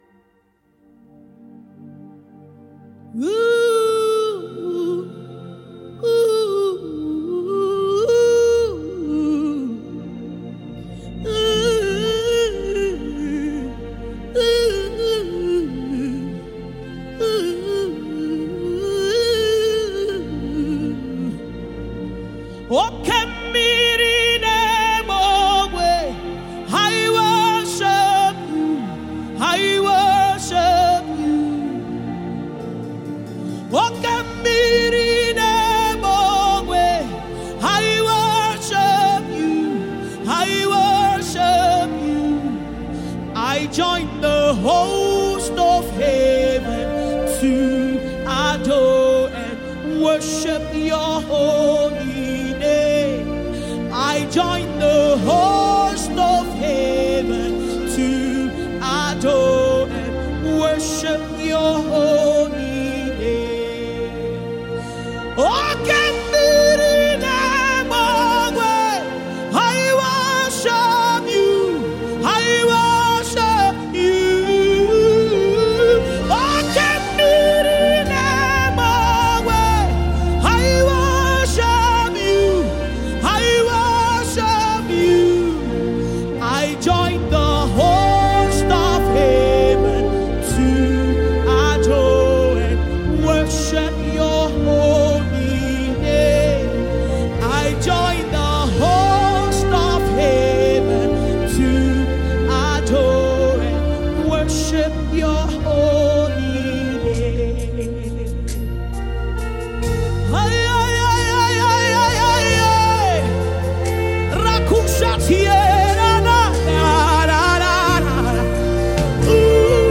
a highly recognized Nigerian gospel singer
soul-uplifting tune